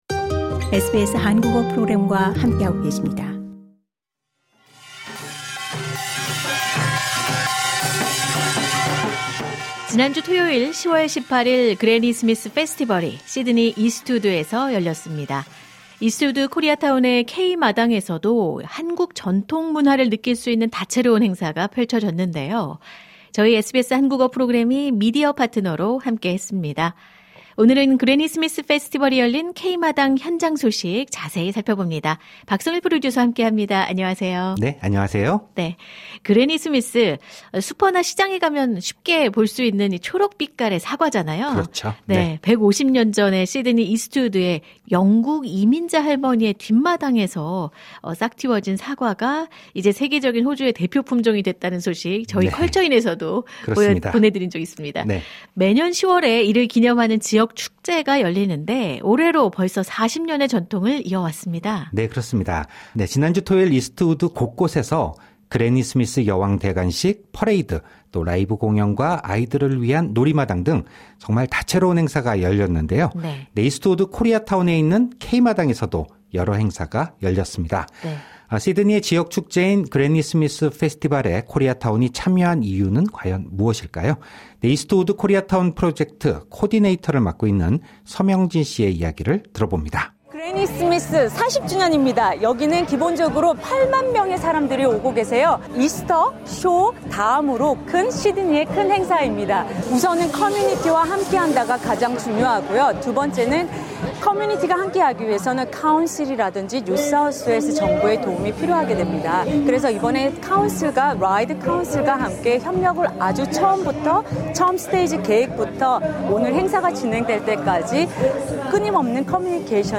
현장 취재: 시드니 사과 축제 ‘그래니 스미스 페스티벌’에 한국 문화가?